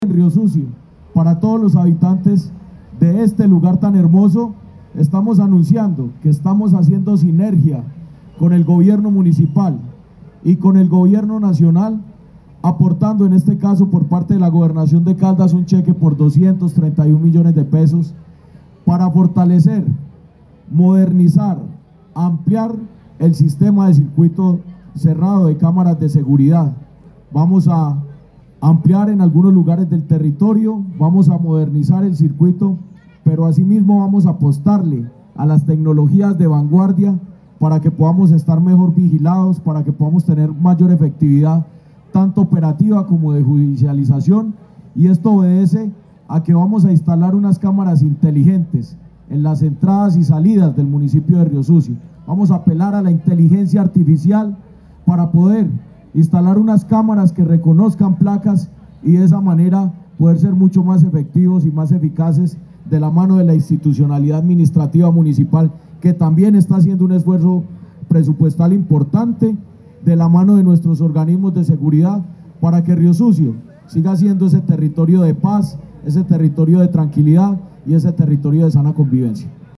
Jorge Andrés Gómez Escudero, secretario de Gobierno de Caldas.